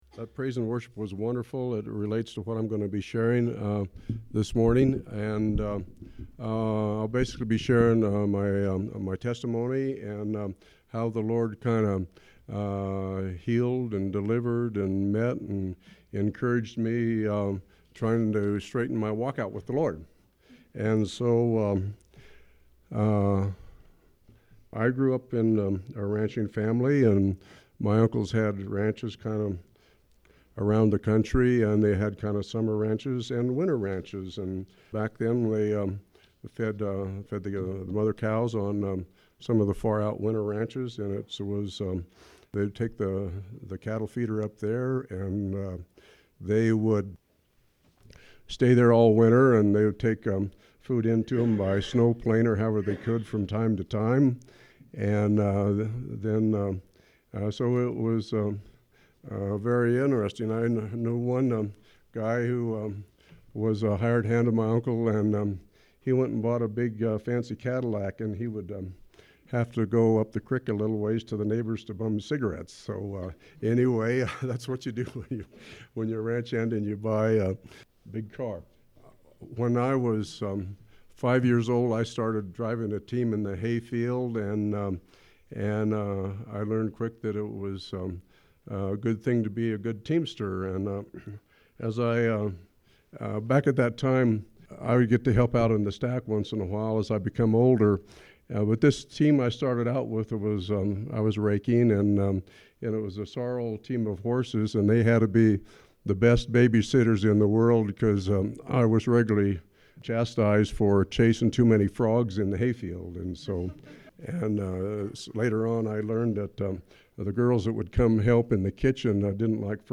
SERMON: Testimony of the power of the Holy Spirit
personal-testimony-of-the-holy-spiri.mp3